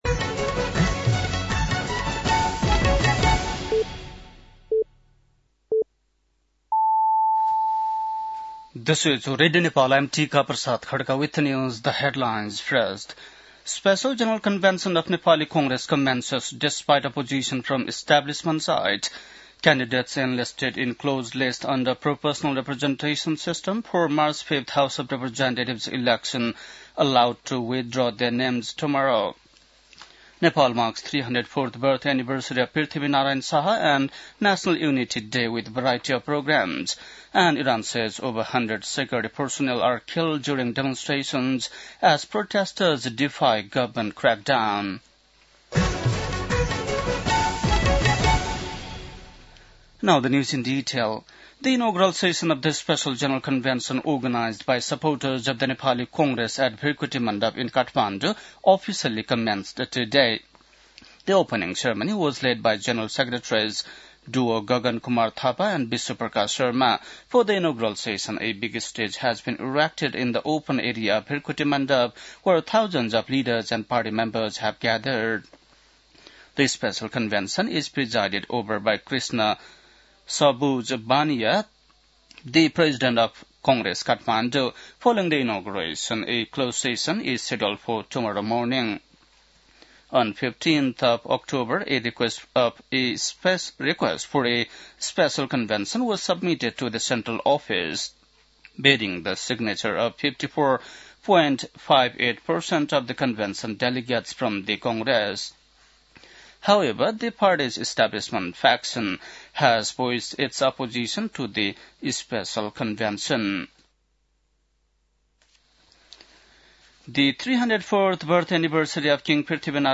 बेलुकी ८ बजेको अङ्ग्रेजी समाचार : २७ पुष , २०८२
8-pm-english-news-9-27.mp3